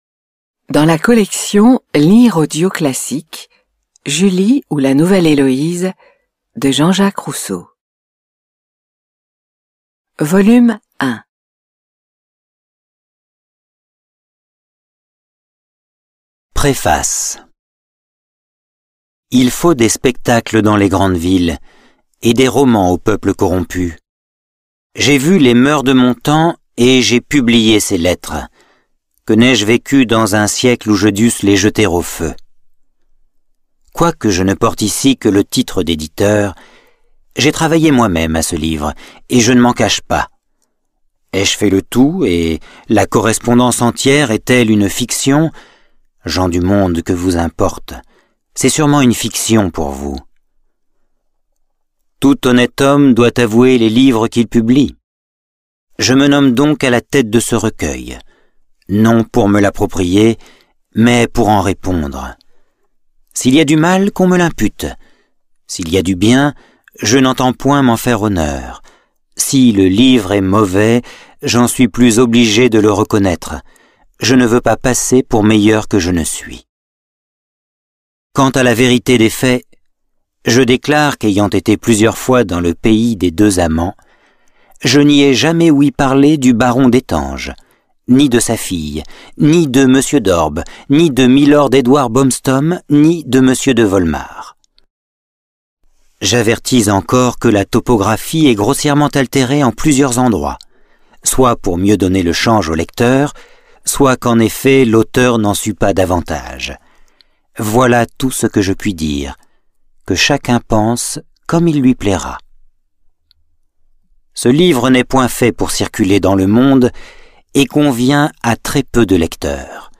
Diffusion distribution ebook et livre audio - Catalogue livres numériques
Lire un extrait - Julie ou la Nouvelle Héloïse de Jean-Jacques Rousseau